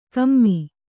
♪ kammi